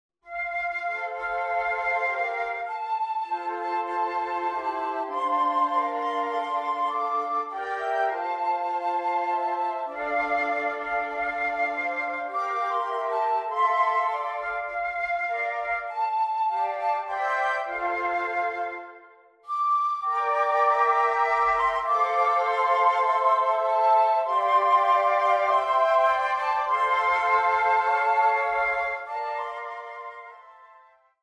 traditional folk melodies
The four parts are of mixed abilities.